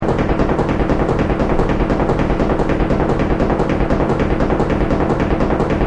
De standaard klopmachine bevat een draaiende as met nokken die hamers optillen dewelke achtereenvolgens neervallen op de vloerconstructie.
Ze vallen aan een ritme van 10 impacten per seconde.
Voor deze demo werd het signaal in de ruimte onder de ruimte waar de klopgeluidmachine werd opgesteld, opgenomen.
klopgeluid.mp3